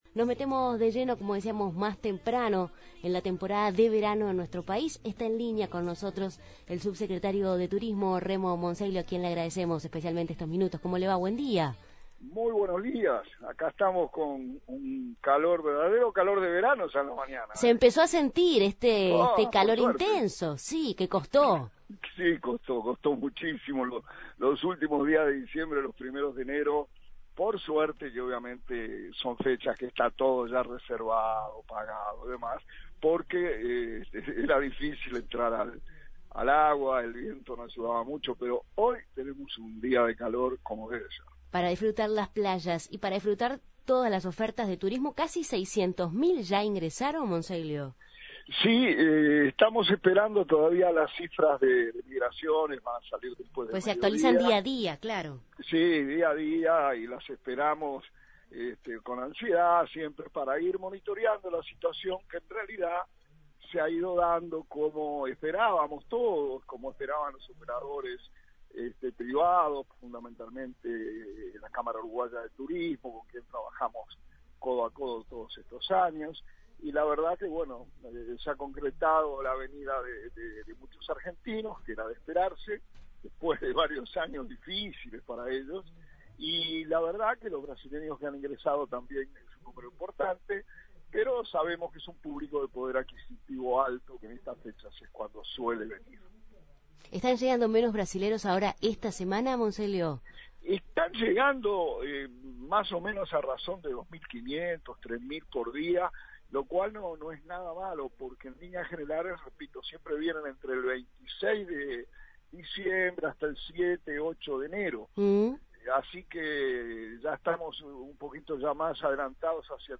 En Justos y pecadores entrevistamos a Remo Monzeglio, subsecretario de Turismo de Uruguay, sobre la afluencia de turistas en la temporada estival